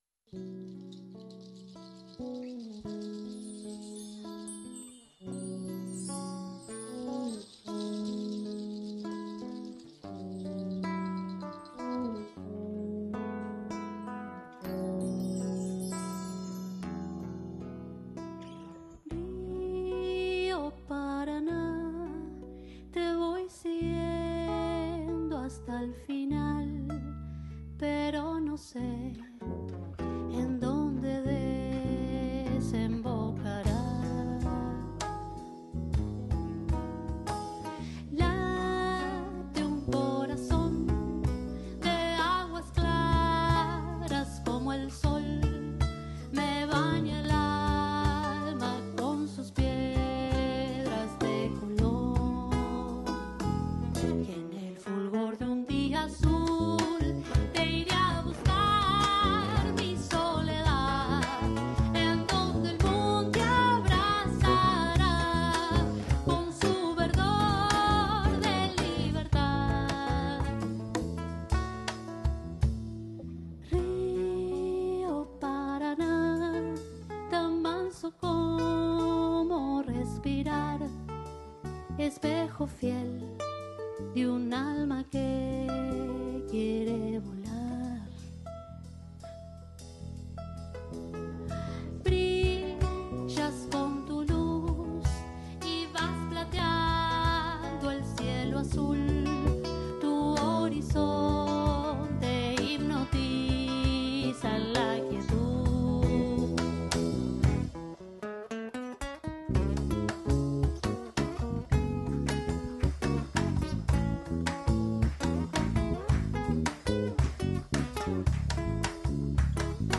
recorre ritmos latinoamericanos
PRESENCIA EN PISO